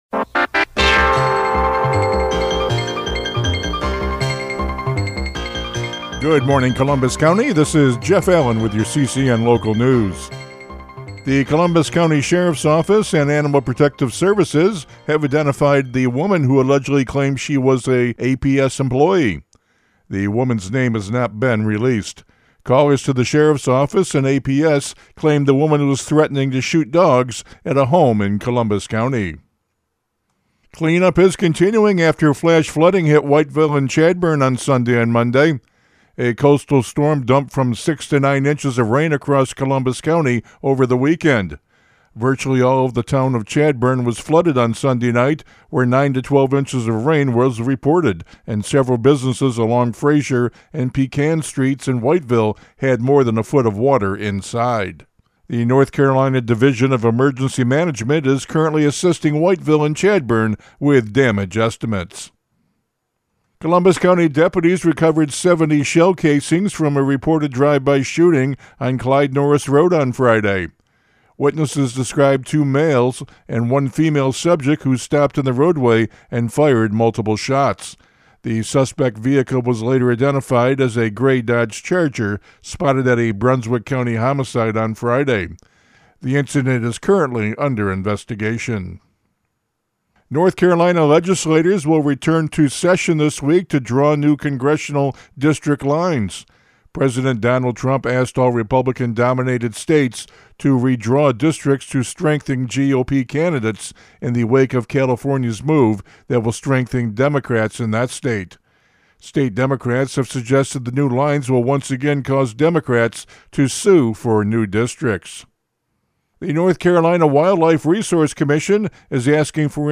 CCN Radio News — Morning Report for October 15, 2025